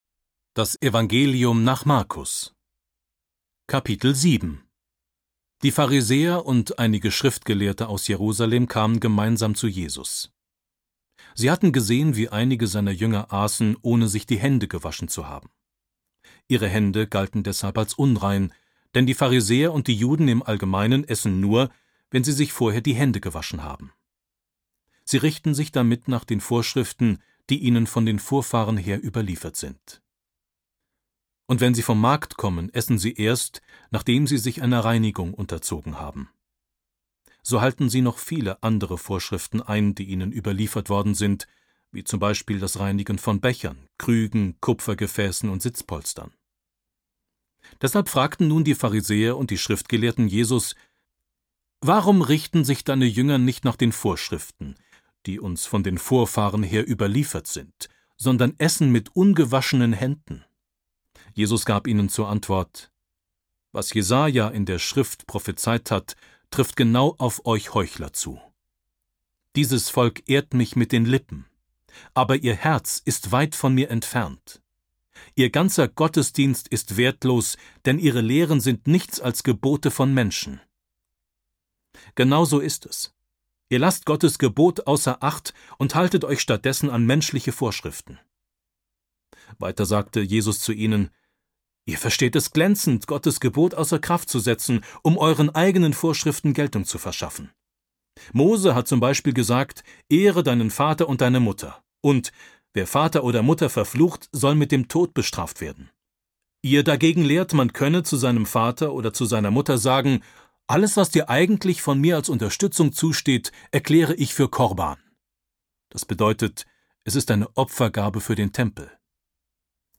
NGÜ. Neues Testament. Hörbibel. 2 MP3-CDs | Die Bibel